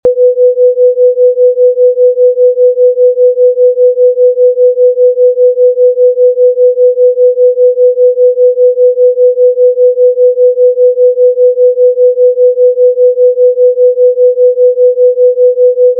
В записи ниже я взял две синусоидальные волны (одну на 500 Гц и одну на 495 Гц) и панорамировал их сильно влево и сильно вправо. Наденьте наушники и проверьте, слышите ли вы этот третий тон, который представляет собой разницу в 5 Гц между двумя волнами.